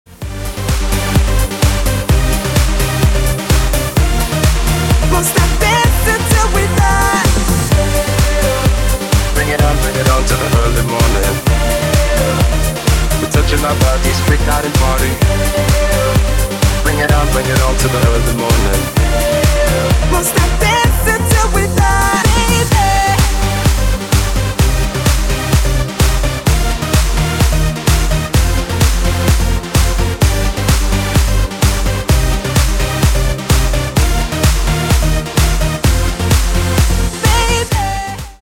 • Качество: 256, Stereo
мужской вокал
громкие
dance
EDM
электронная музыка
progressive house
Trance